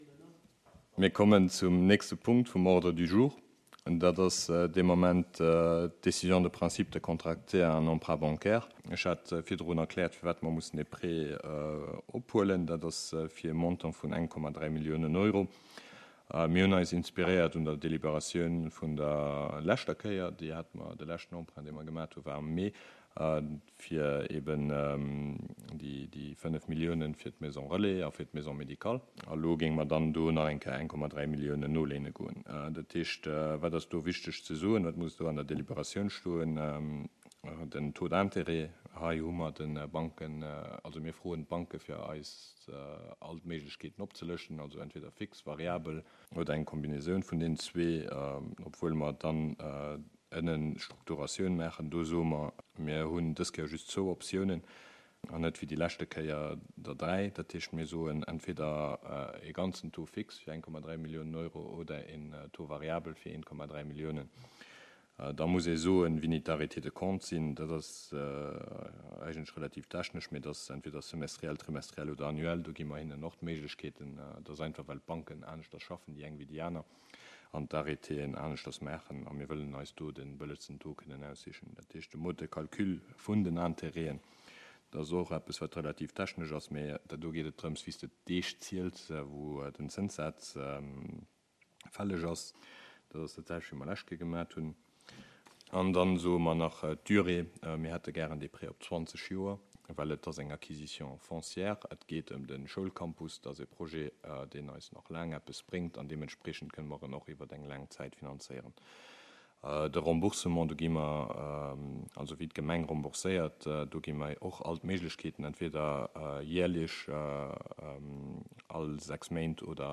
Conseil Communal  du mercredi 28 septembre 2022 à 19h00 heures Centre Culturel Larei en la salle Bessling